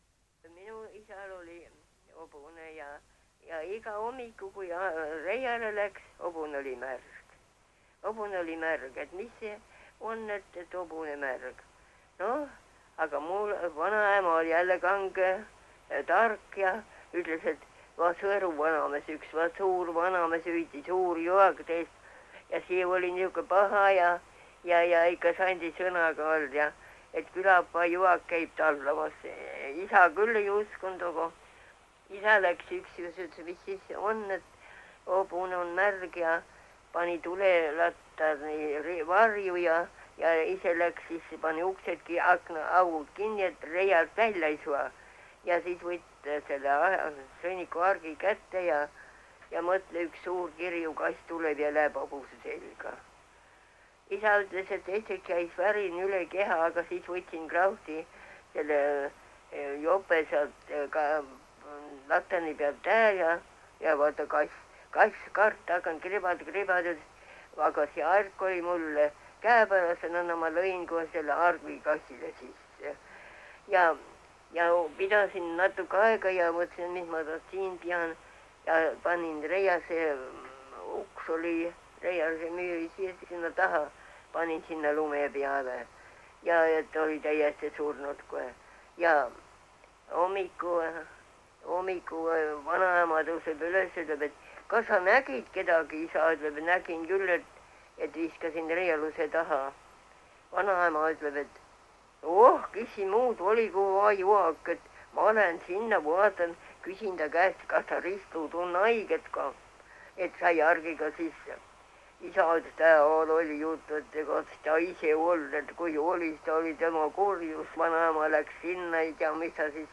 MurdekiikerKeskmurreKJüri